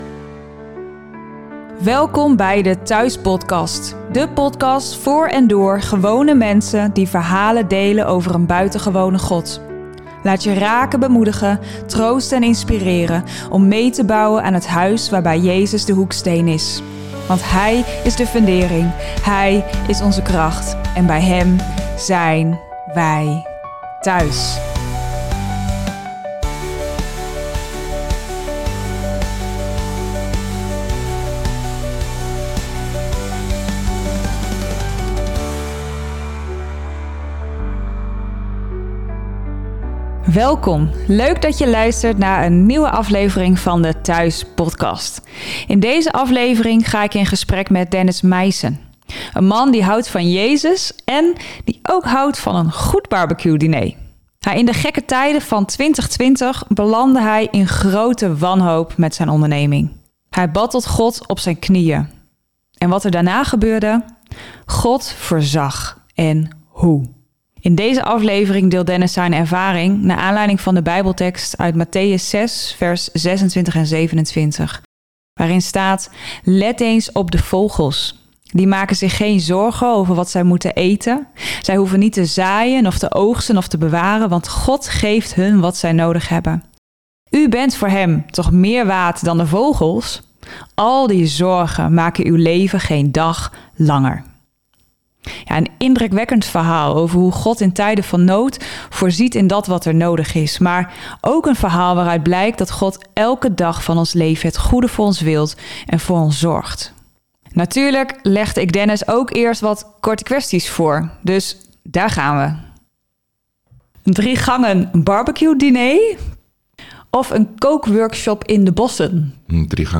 ---------------------------------------------------------------------------------------------------- Om de week op vrijdag komt er een nieuwe aflevering online waarbij hele gewone mensen, verhalen delen over een buitengewone God.